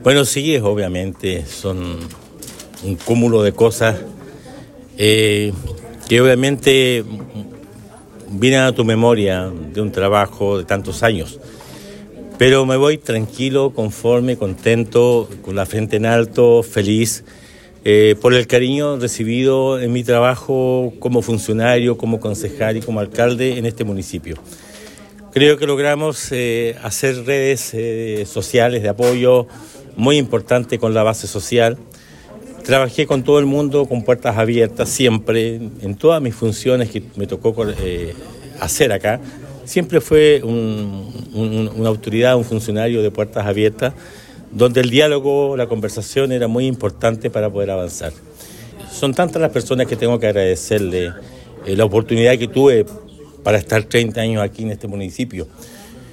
En sesión ordinaria de Concejo Municipal realizada este martes 12 de noviembre, el alcalde de Osorno, Emeterio Carrillo Torres, presentó la renuncia a su cargo, la que se hará efectiva a contar del próximo sábado 16 de noviembre.